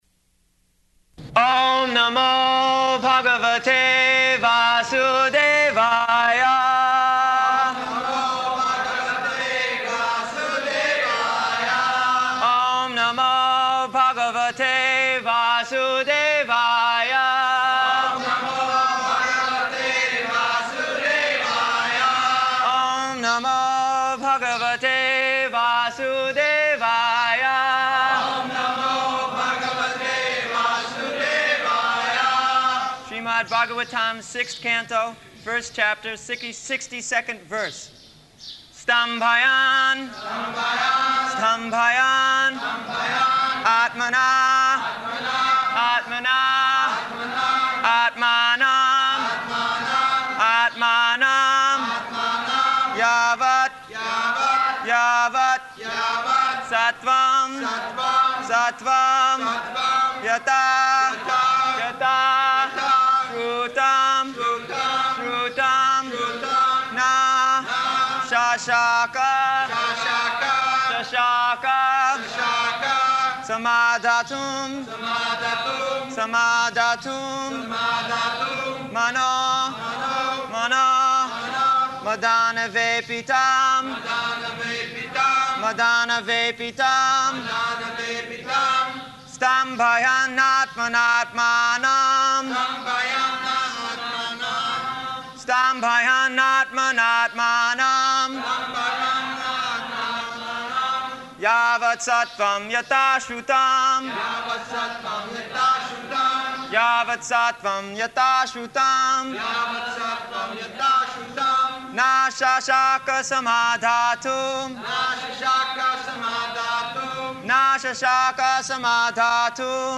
August 29th 1975 Location: Vṛndāvana Audio file